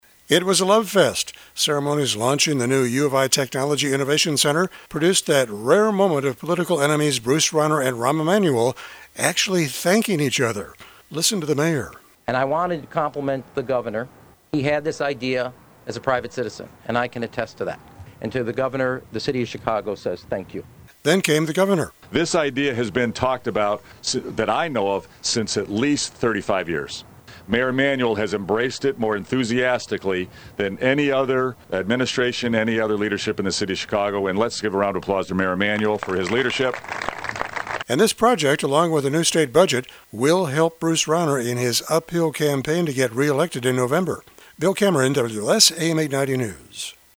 Ceremonies launching the new U of I Technology Innovation center produced that rare moment of political enemies Bruce Rauner and Rahm Emanuel thanking each other.